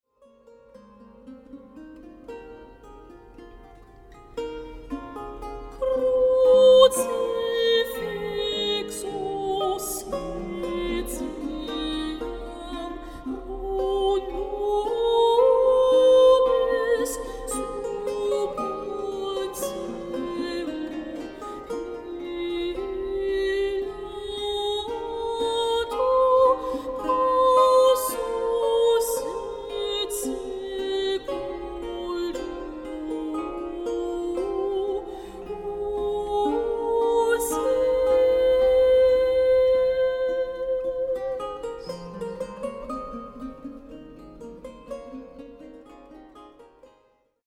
Sopran
I Fedeli Basel